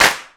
Clap23.wav